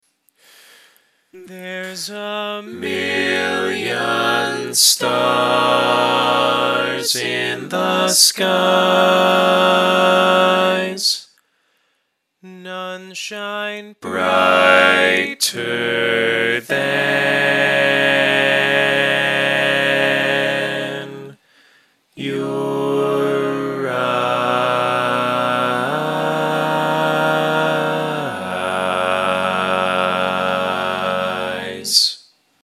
Key written in: G♭ Major
Type: Barbershop